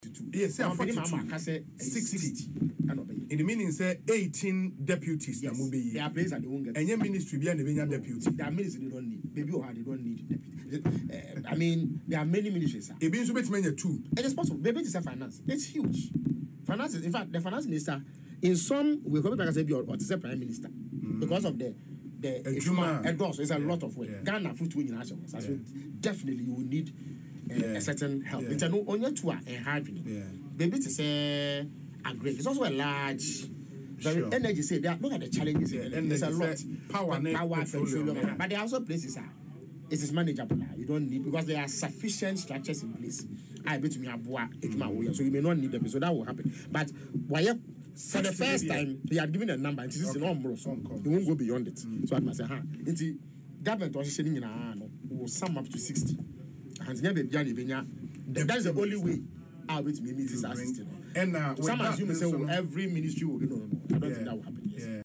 In an interview on Asempa FM’s Ekosii Sen, Mr. Ofosu noted that some ministries will require two deputies, while others will not have any.